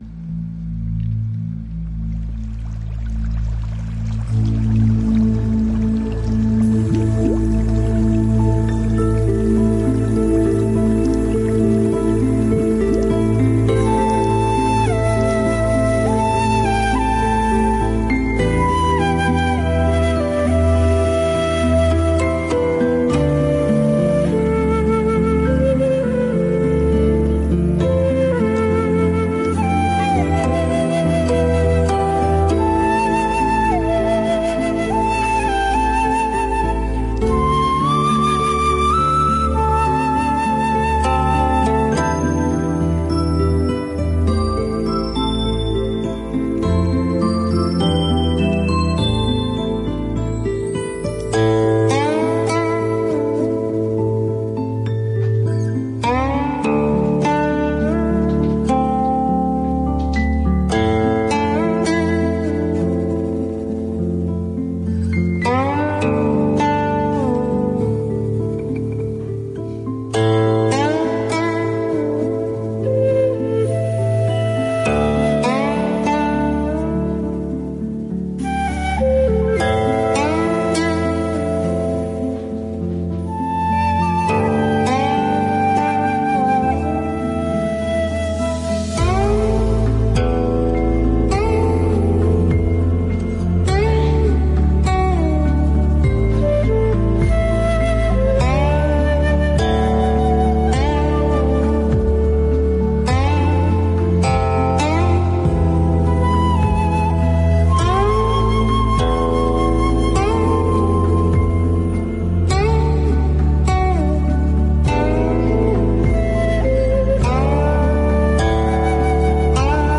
佛音 冥想 佛教音乐 返回列表 上一篇： 弄戏--刘珂矣 下一篇： 语烟